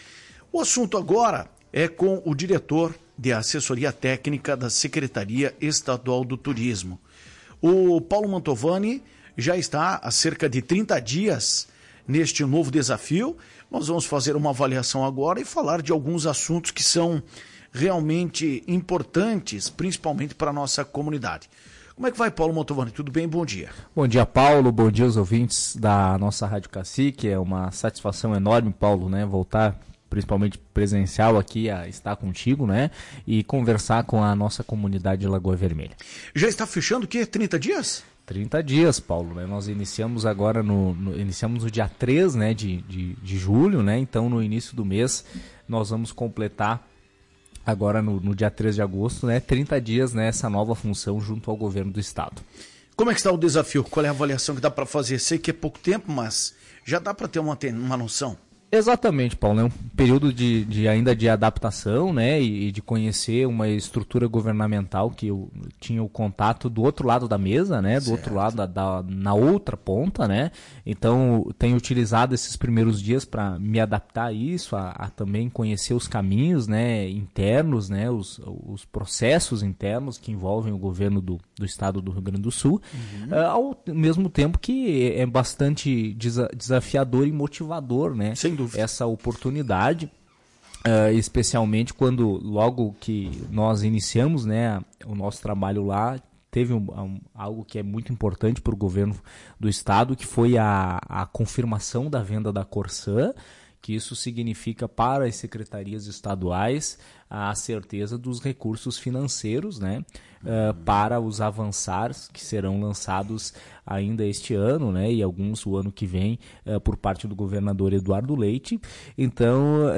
Em entrevista, diretor de Assessoria Técnica falou sobre primeiros dias no cargo